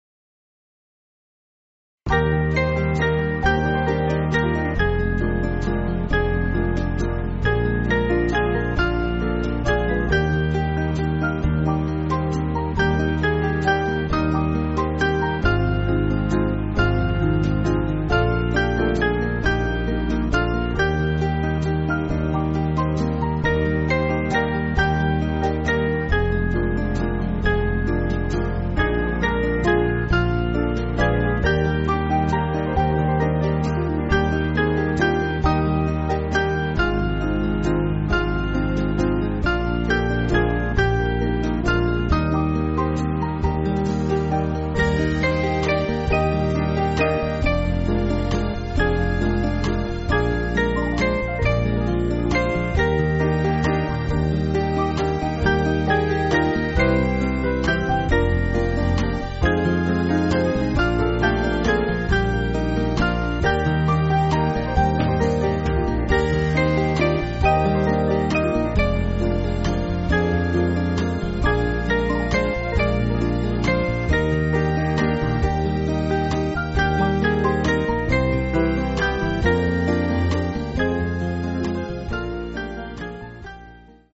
Small Band
(CM)   3/Eb